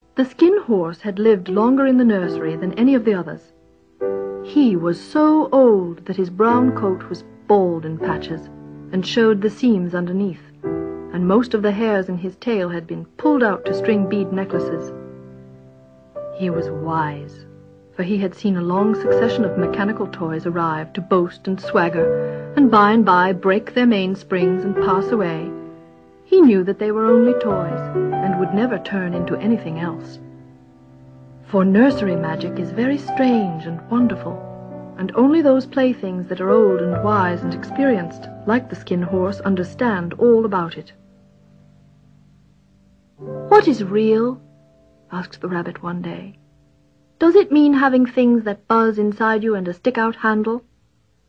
A Christmas gift that is ignored, then beloved, and then abandoned, the rabbit with the pink sateen ears comes to love the boy who takes him everywhere and through that love finds literal rebirth. Meryl Streep doesn't disappoint as she liltingly brings the toy rabbit to life - with the help of the nursery magic fairy. Streep's reading is complemented by George Winston's music and illustrations by David Jorgenson.
The Velveteen Rabbit was released both as an audio book and in an animated version on video cassette.